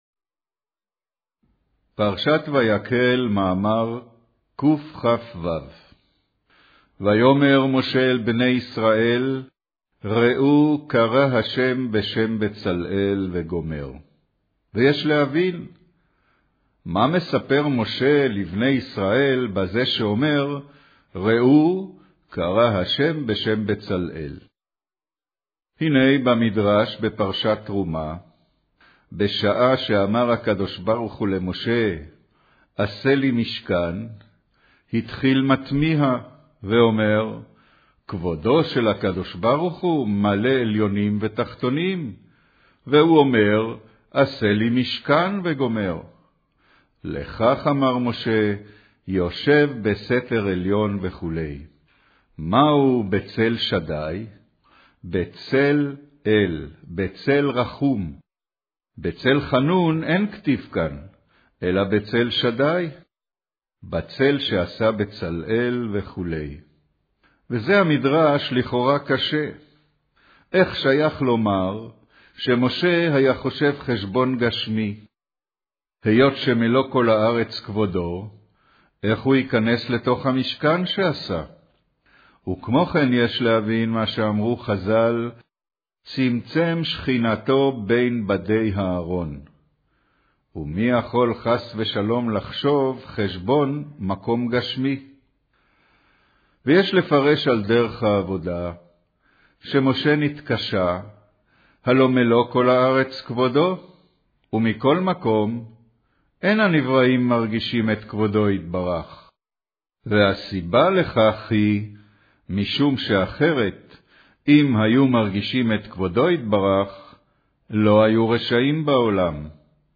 קריינות פרשת ויקהל, מאמר ויאמר משה אל בני ישראל ראו קרא ה' בשם בצלאל